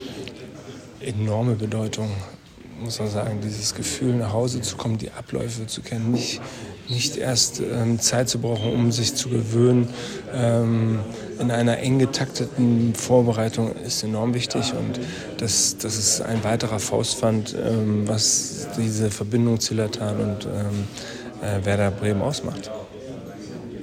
O-Töne sind unter folgendem Link abrufbar: